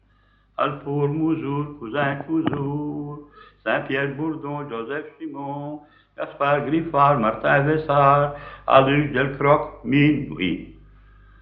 Genre : chant
Type : comptine, formulette
Lieu d'enregistrement : Monceau-Imbrechies
Support : bande magnétique